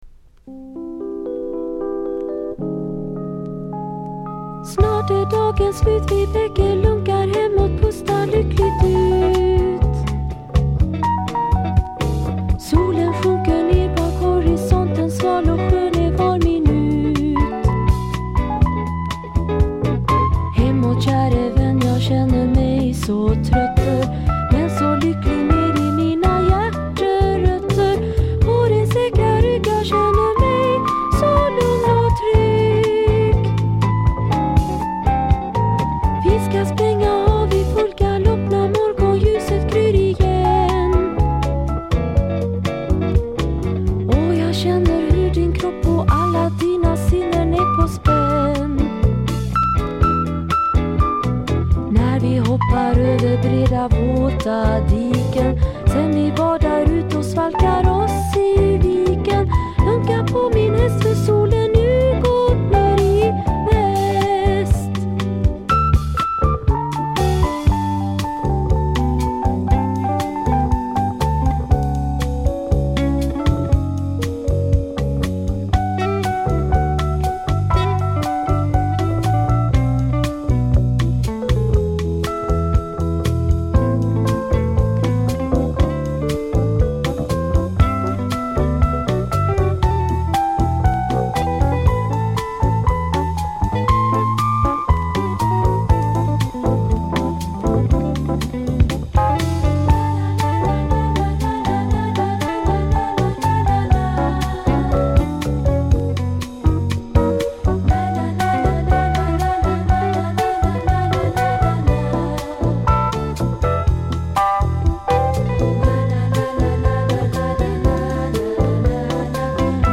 しかしそこに詰まっているのは、いかにも北欧らしい牧歌的ボサノヴァの数々。